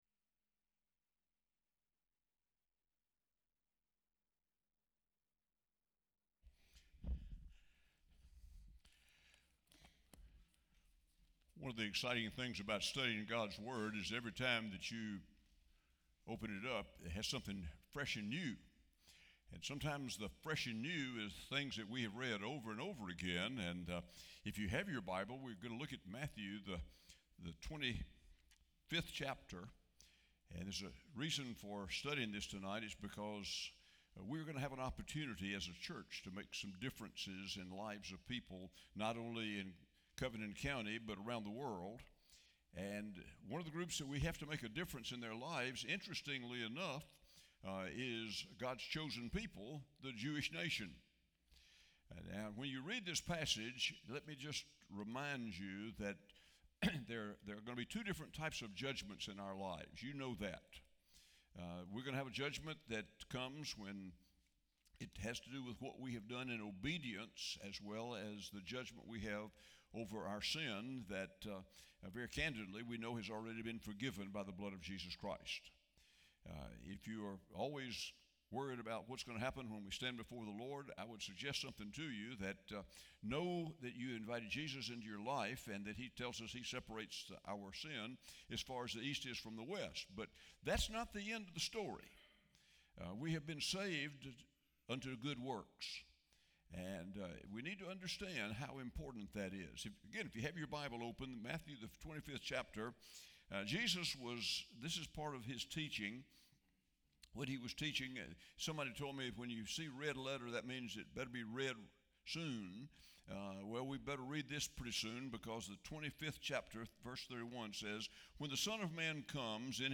FIRST BAPTIST CHURCH OPP Sermons 2020